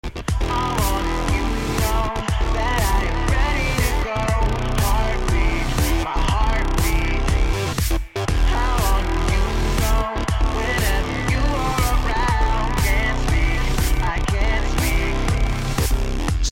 Heartbeat.